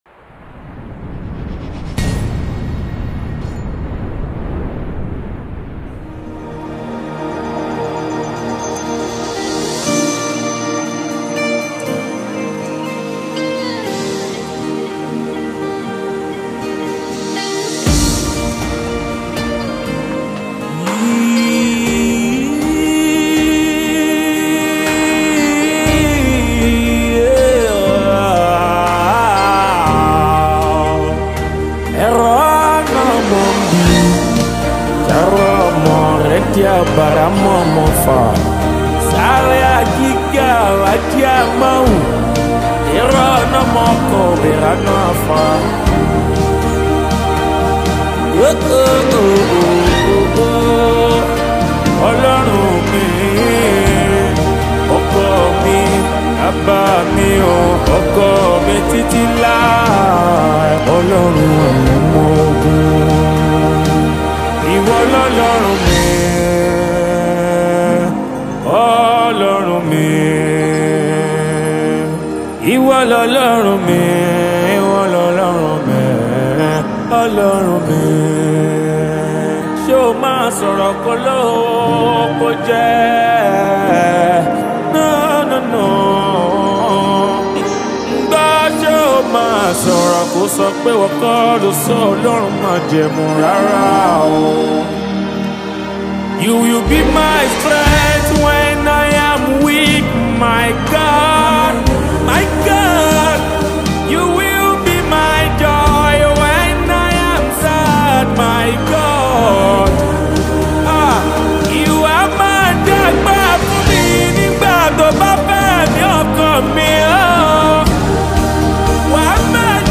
Yoruba Fuji track
Nigerian Yoruba Gospel track
Yoruba Fuji Sounds
be ready to dance to the beats